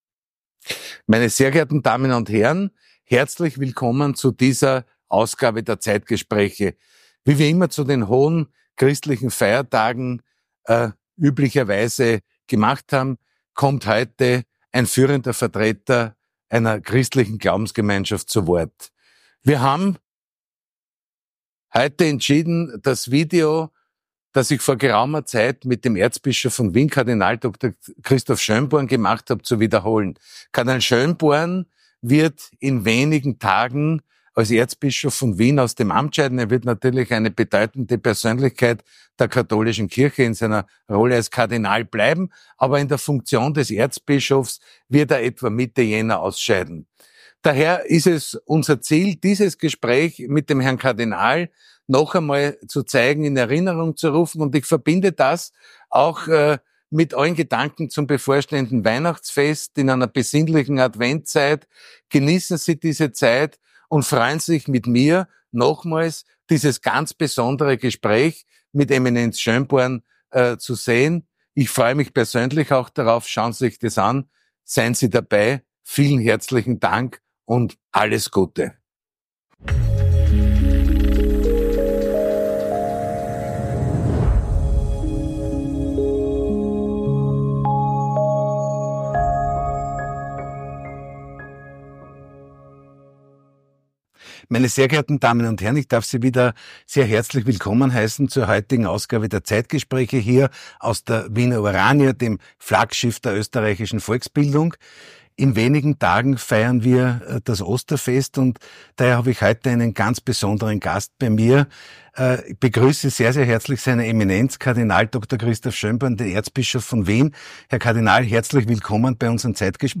Das Interview mit Kardinal Dr. Christoph Schönborn aus 2021 behandelt zentrale Themen des Christentums, der Gesellschaft und der aktuellen Herausforderungen, mit denen Kirche und Gläubige konfrontiert sind.